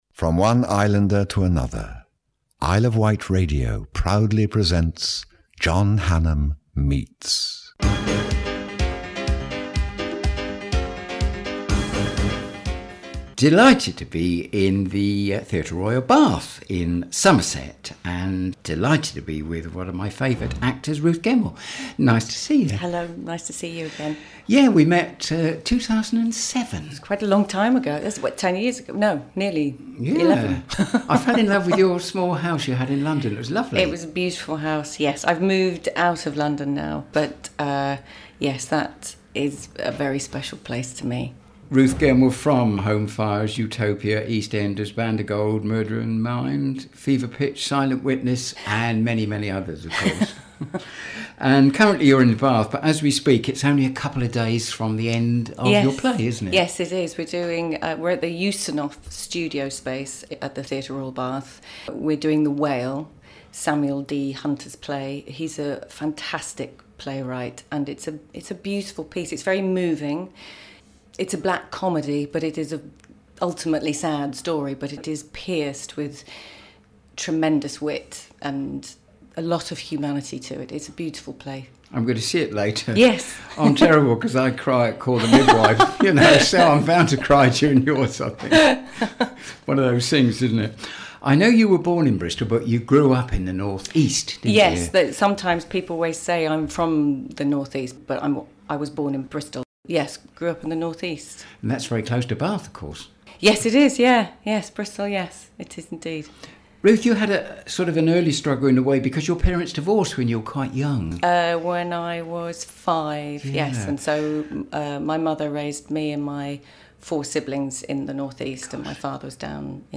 interviews celebrities on their life and work